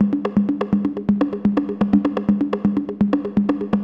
cch_percussion_loop_darkness_125.wav